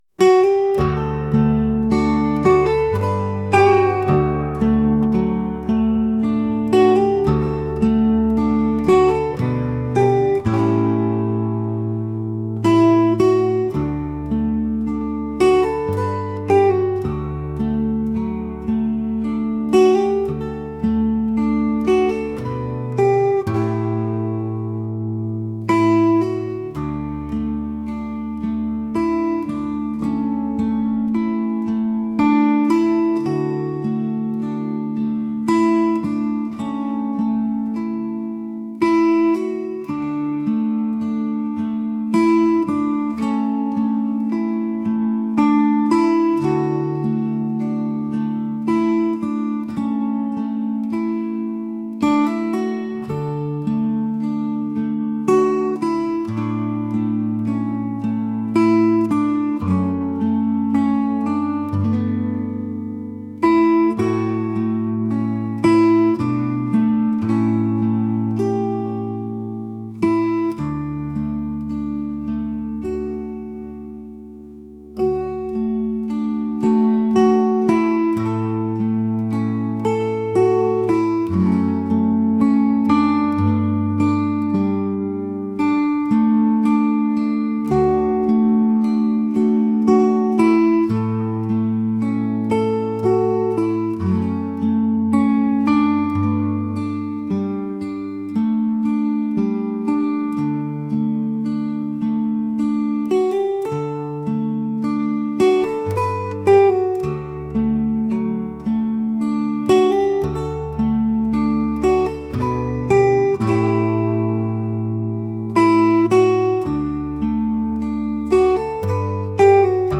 traditional | folk